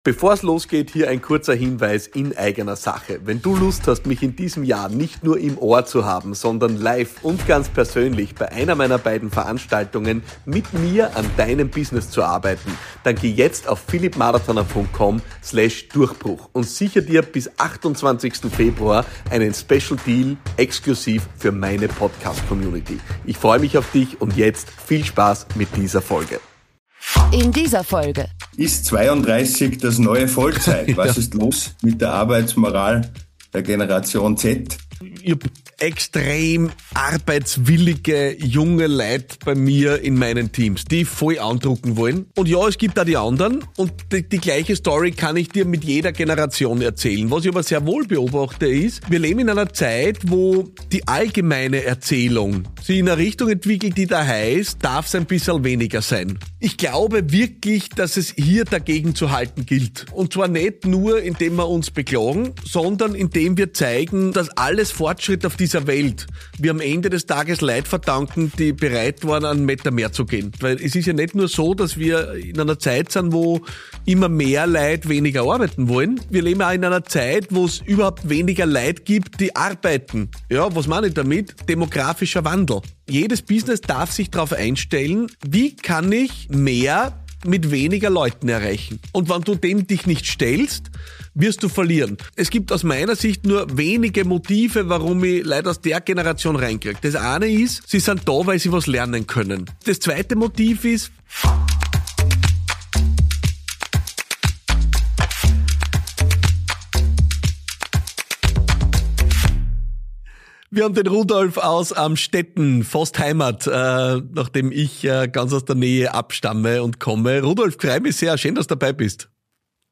1 #282 - 3 entscheidende Tipps für überzeugende Auftritte – Wie Du jedes Publikum für Dich gewinnst! 22:32 Play Pause 23h ago 22:32 Play Pause Putar nanti Putar nanti Daftar Suka Menyukai 22:32 Lerne in diesem Interview von Knacki Deuser, dem erfahrenen Entertainer und Kommunikationsexperten, wie Du jedes Publikum für Dich gewinnen kannst.